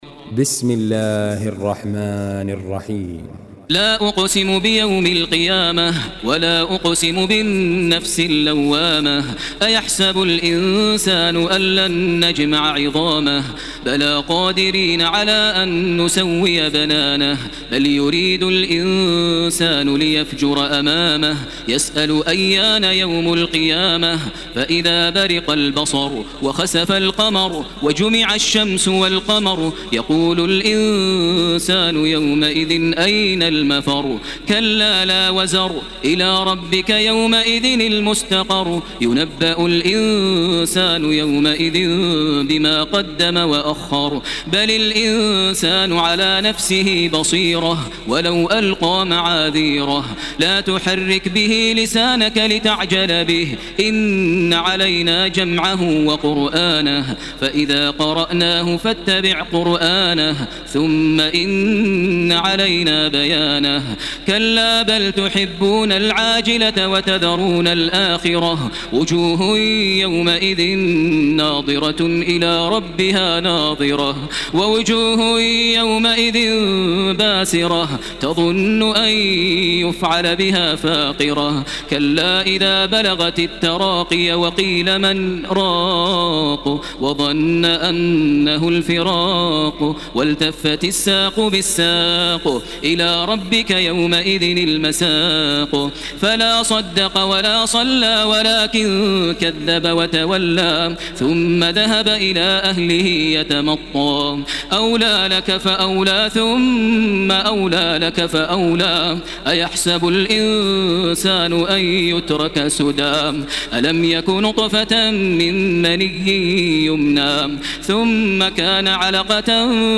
Surah Al-Qiyamah MP3 by Makkah Taraweeh 1433 in Hafs An Asim narration.
Murattal Hafs An Asim